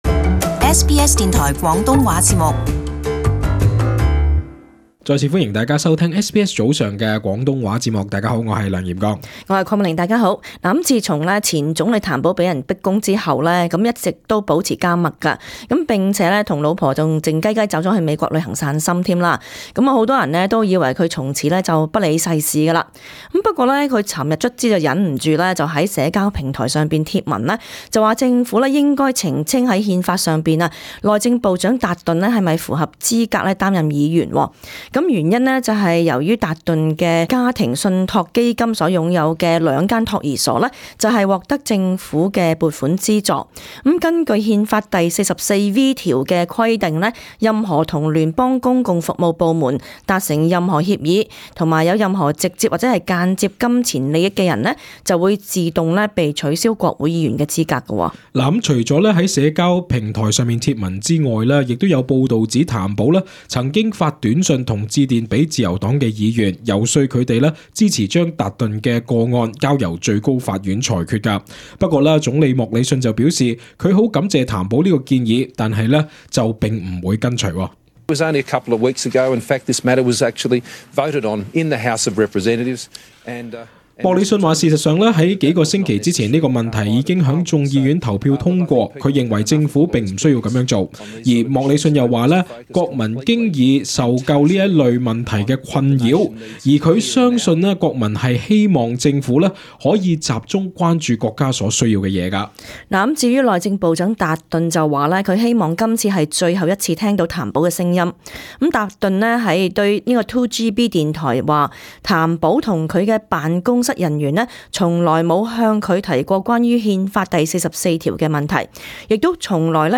【時事報導】譚保籲政府澄清達頓議員資格